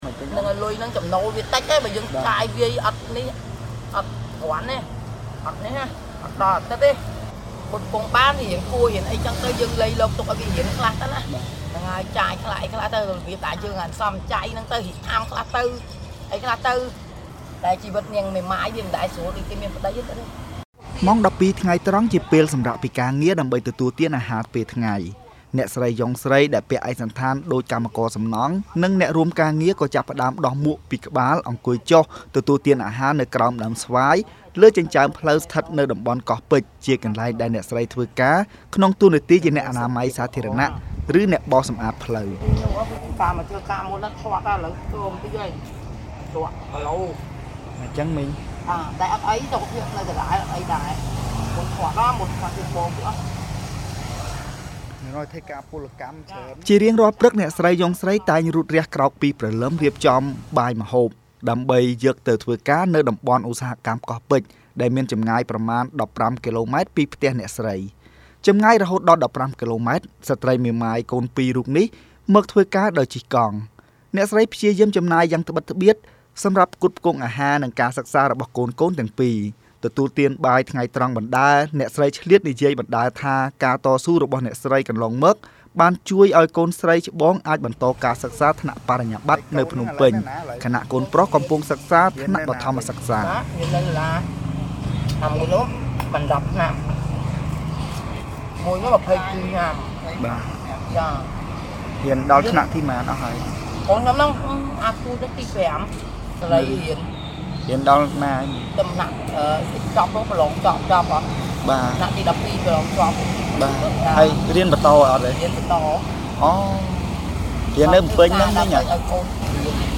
បទយការណ៍